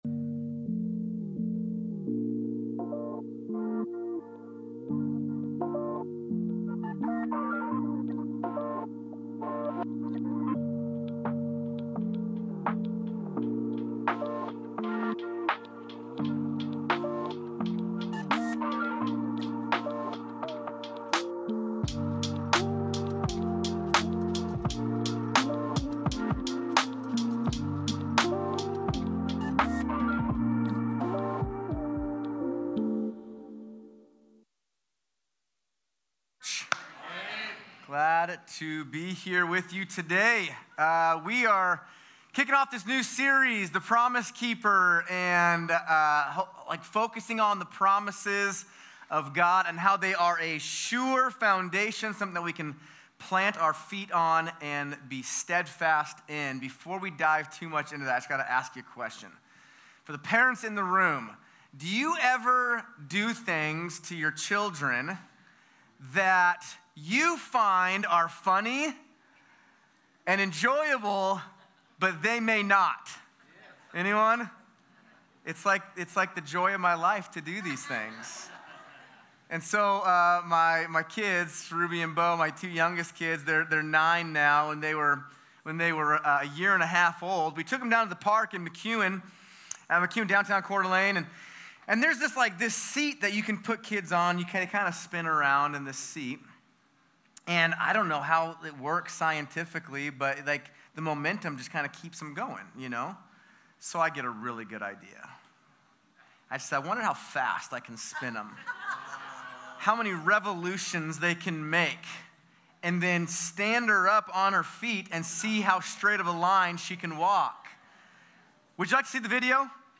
25.08.11+Sermon.mp3